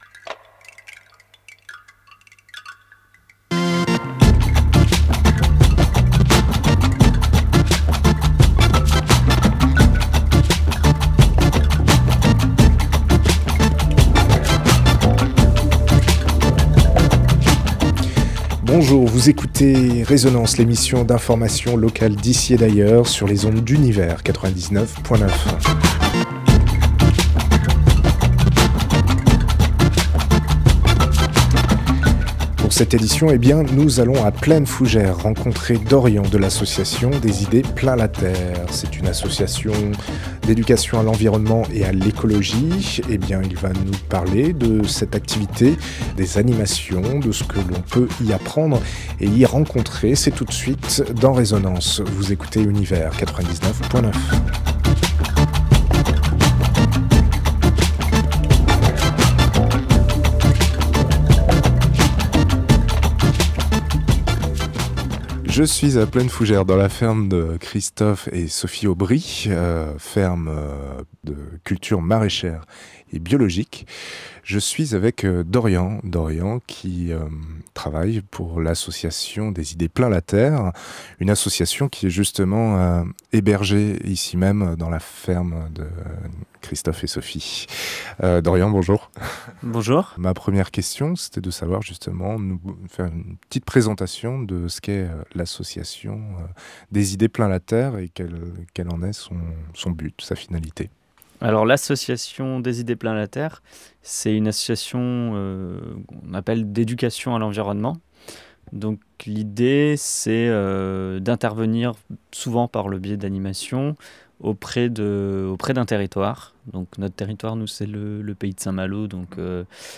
Elle présente l’association éponyme d’éducation à l’environnement qui intervient sur le territoire du Pays de Saint-Malo. Entretien